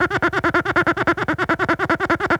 cartoon_squeaky_cleaning_loop_07.wav